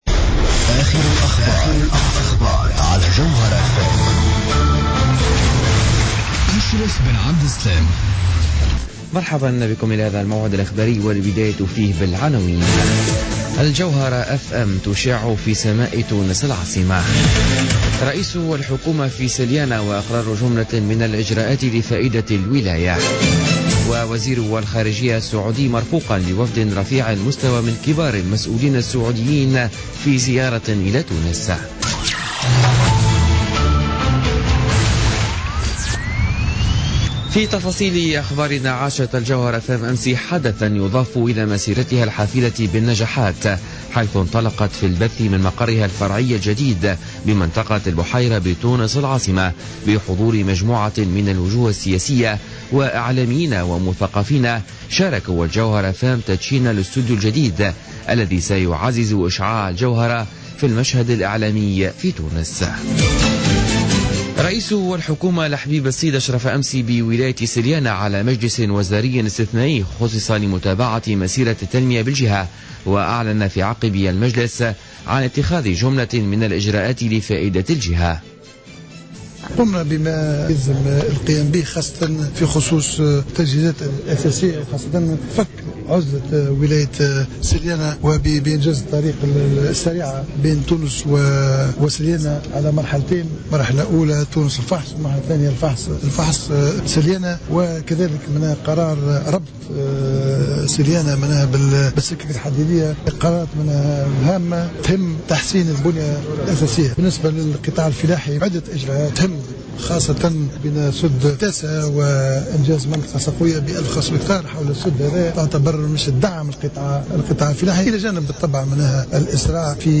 نشرة أخبار منتصف الليل ليوم الاربعاء 30 ديسمبر 2015